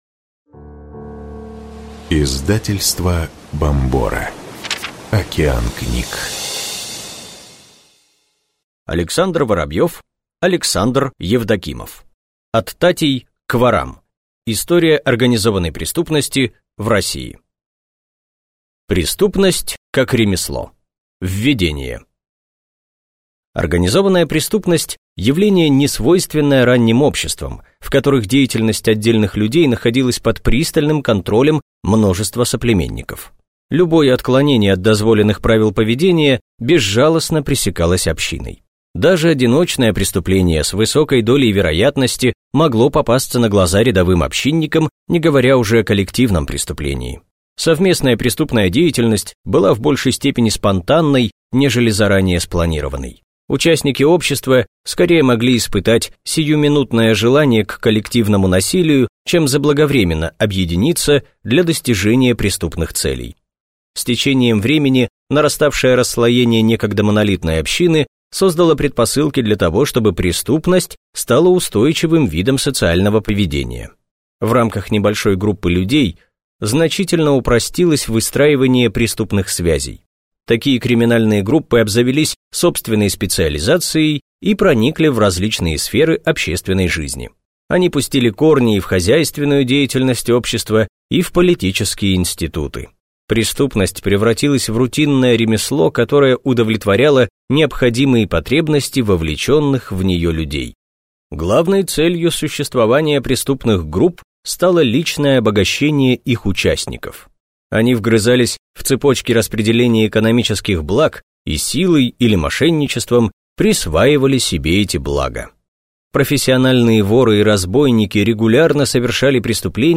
Аудиокнига От татей к ворам. История организованной преступности в России | Библиотека аудиокниг